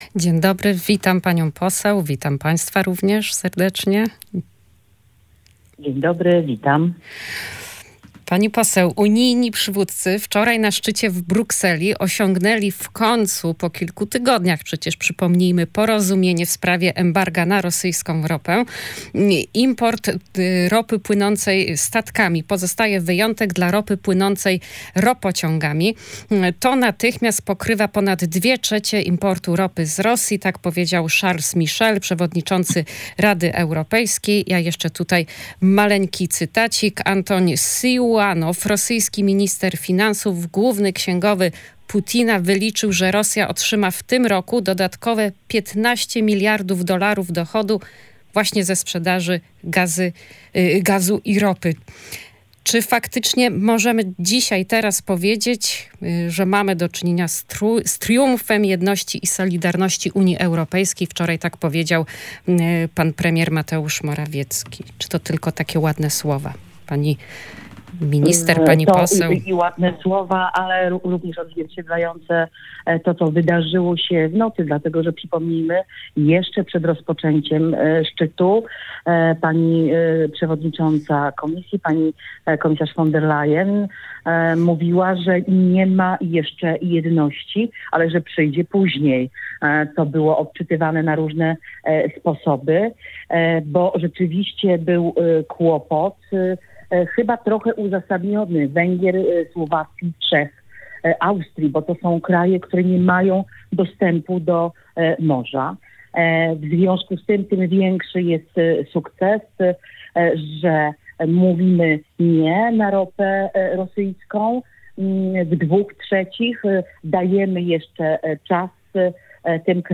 Dla nich nie ma żadnej granicy. Nie liczą się Polacy, najważniejsze jest odsunięcie PiS od władzy. To ich priorytet i wszystkie środki, łącznie z zabieraniem Polsce pieniędzy i szkalowaniem jej dobrego imienia, są dla tych panów absolutnie dopuszczalne – powiedziała na antenie Radia Gdańsk Anna Zalewska, posłanka do Parlamentu Europejskiego z ramienia Prawa i Sprawiedliwości.